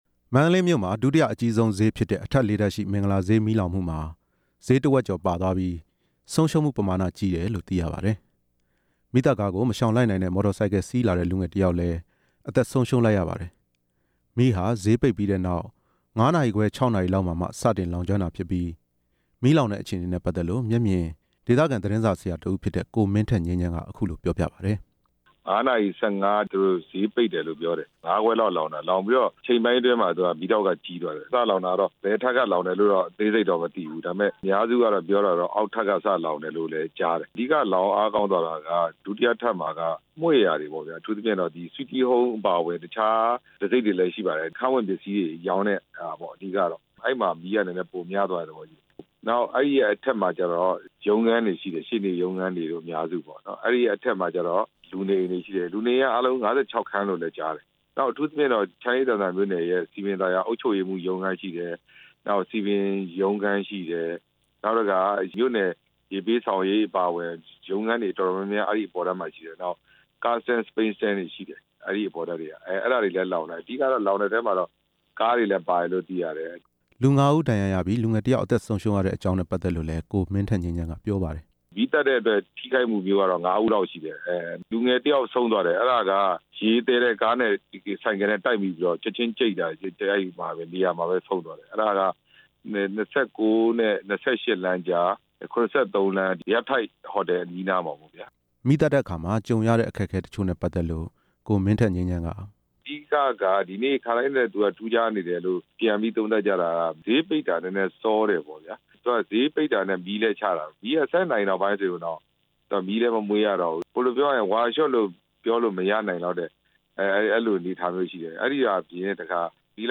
မင်္ဂလာဈေး မီးလောင်ဆုံးရှုံးမှုနဲ့ ပတ်သက်လို့ မျက်မြင်သက်သေနဲ့ ဆိုင်ခန်းပိုင်ရှင်ရဲ့ ပြောဆိုချက်ကို